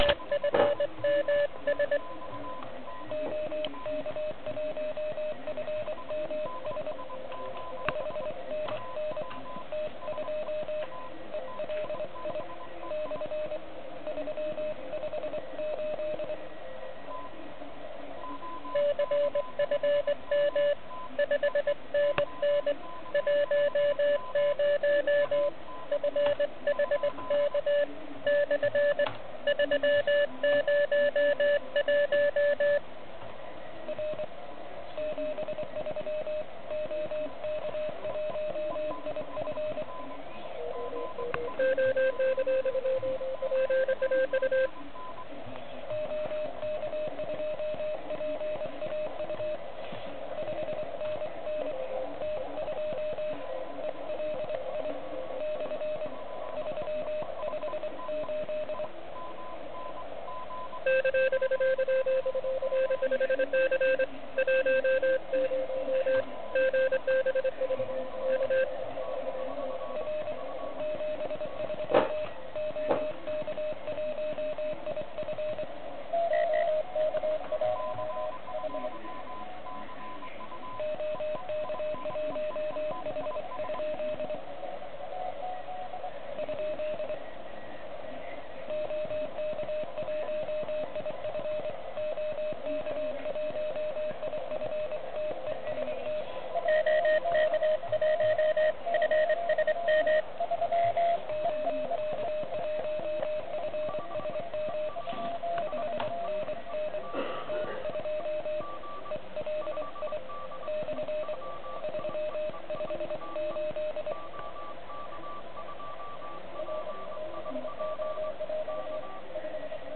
Je mi jasné, že filtr 200Hz (i když s blbým Q) není pro OK QRP závod to nejideálnější.